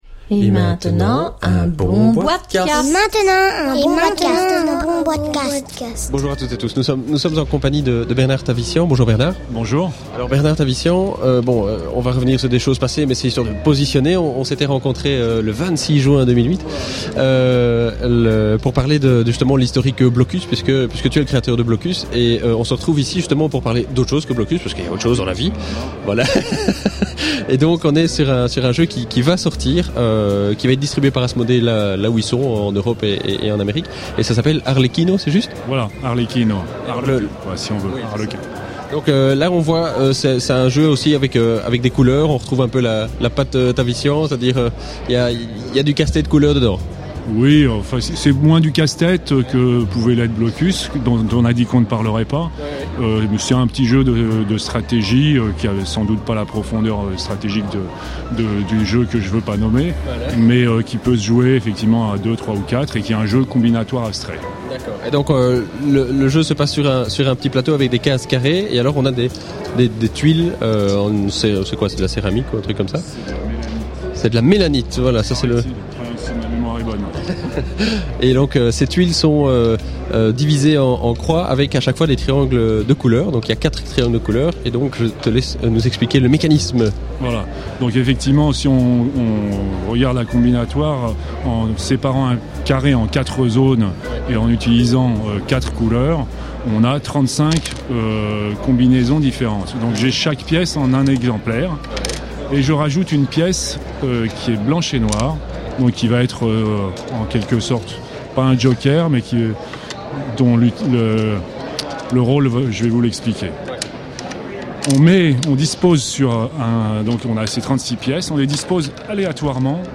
(enregistré lors du salon international de la Nuremberg Toy Fair 2010)